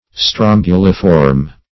Search Result for " strombuliform" : The Collaborative International Dictionary of English v.0.48: Strombuliform \Strom*bu"li*form\, a. [NL. strombulus, dim. of strombus + -form.